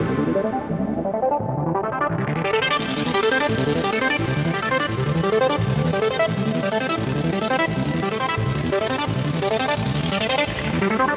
Index of /DESN275/loops/Loop Set - Futurism - Synthwave Loops
BinaryHeaven_86_C_Arp.wav